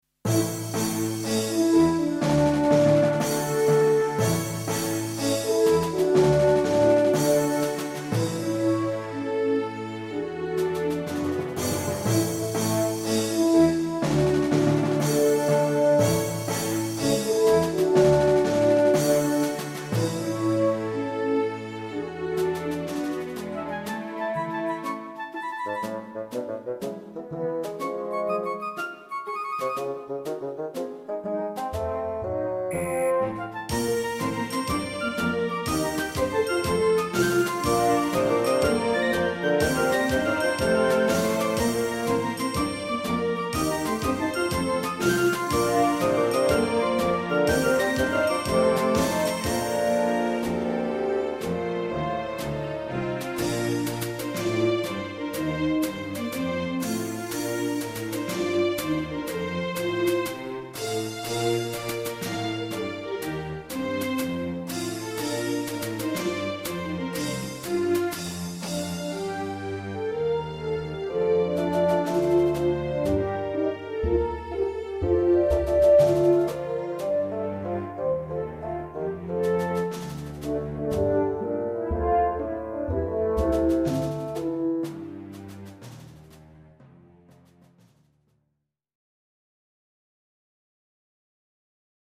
klassiek
Parade van oorlogsveteranen, afwisselend orkest en harmonie